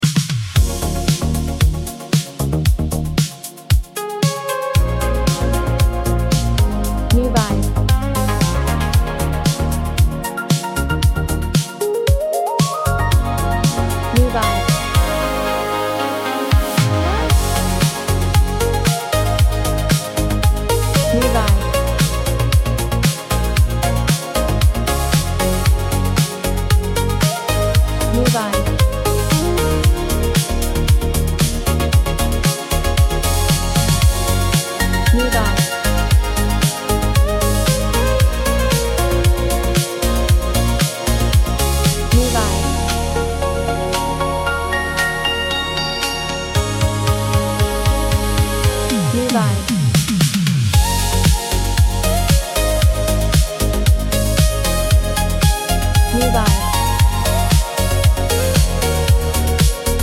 Genre: Dance, 80's